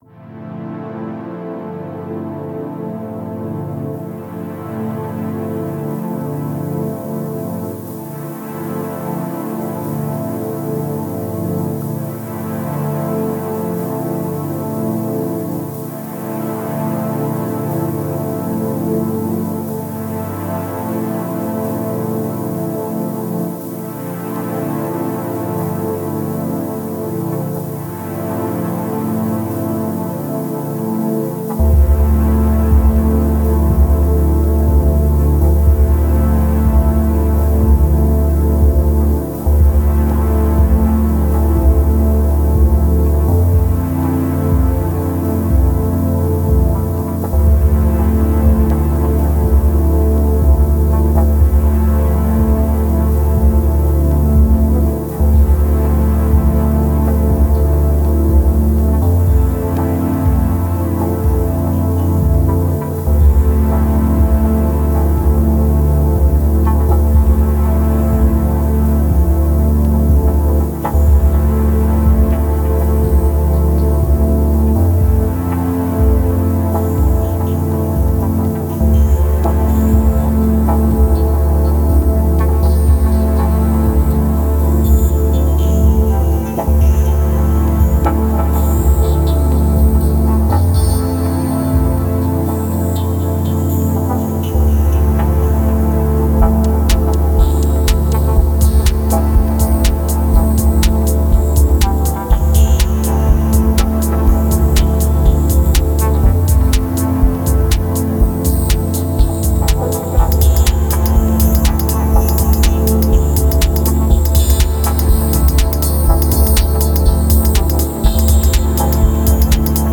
Genre: Dub Techno/Deep Techno.